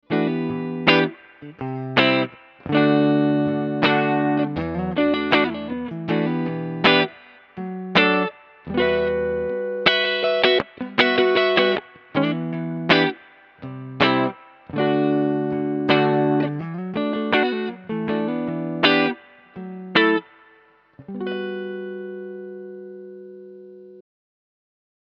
Die Yamaha Revstar RS820CR liefert sehr gute Rock- und Blues Sounds.
Für Fans von warmen, cremigen Gitarrensounds wird die Yamaha Revstar RS820CR ein zufriedenstellendes Ergebnis liefern.
Zwar lassen sich durch den Dry Switch Bässe herausfiltern, jedoch klingen die Tonabnehmer etwas dumpf für meine Ohren.
Yamaha Revstar RS820CR Soundbeispiele